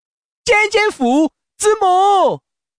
Index of /hunan_feature4/update/12950/res/sfx/changsha_man/